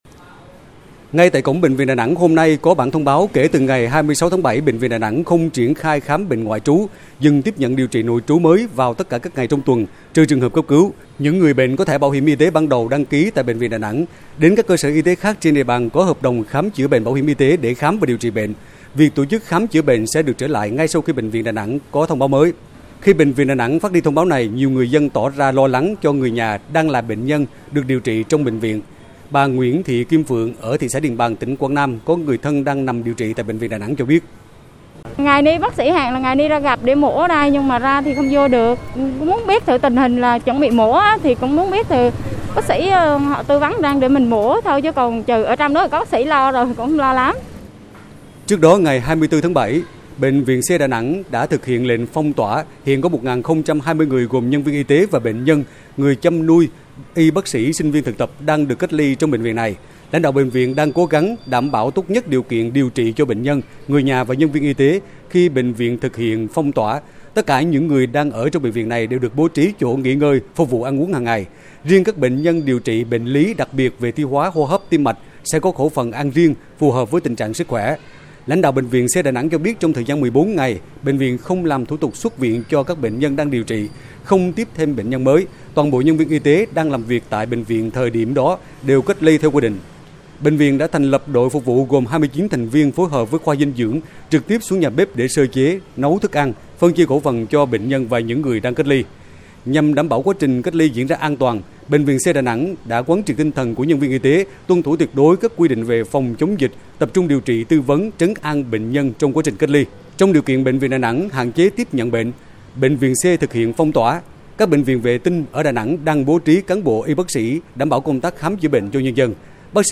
Phản ánh của PV Đài TNVN tại miền Trung: